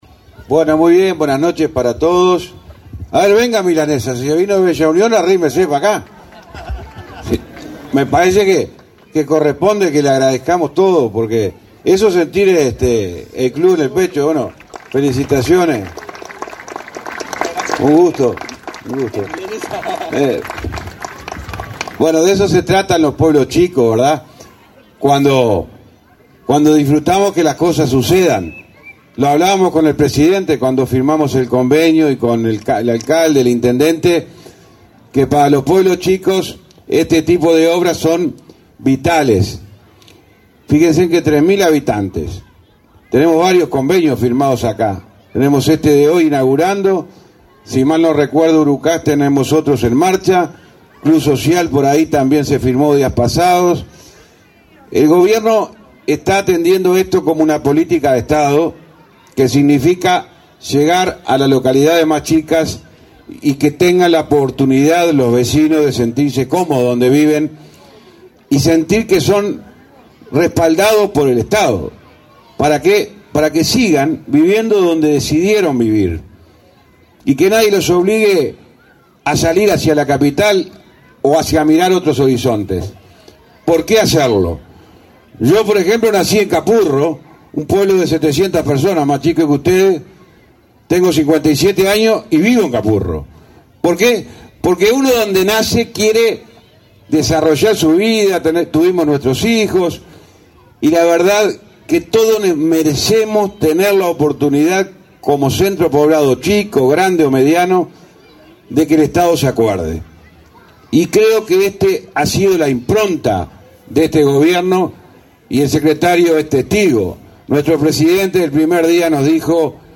Acto de inauguración de obras del MTOP
Participaron del evento el secretario de la Presidencia, Álvaro Delgado, y el ministro José Luis Falero.